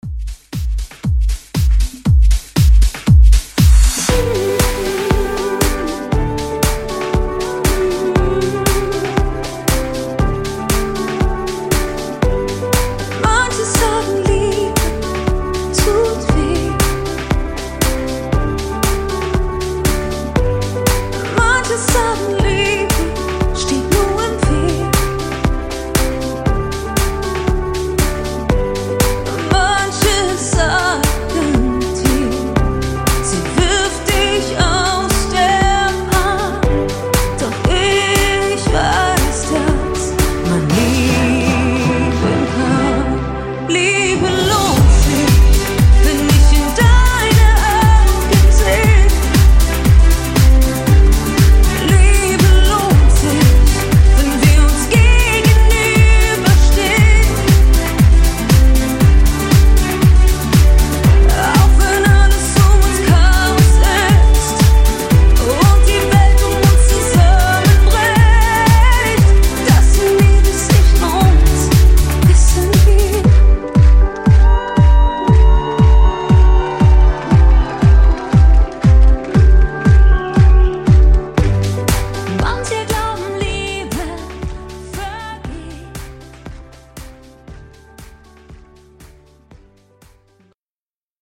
Genres: DANCE , GERMAN MUSIC , RE-DRUM
Clean BPM: 118 Time